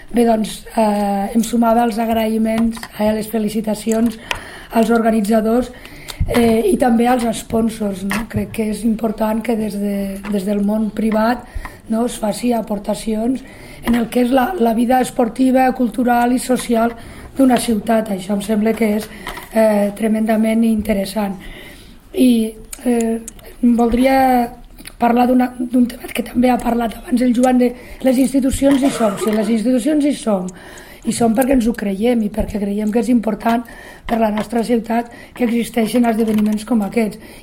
tall-de-veu-de-lalcaldessa-accidental-montse-parra-sobre-la-7a-cursa-bbva-cx-bombers-lleida